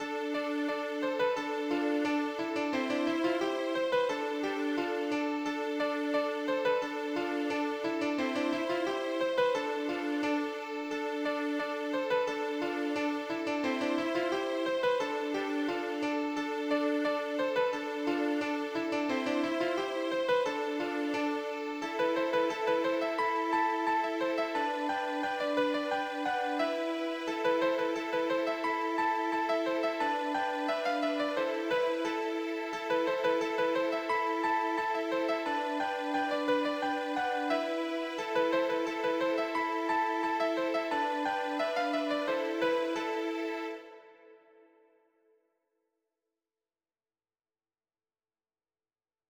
Polka   - Page de garde